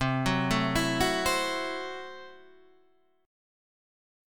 C6b5 chord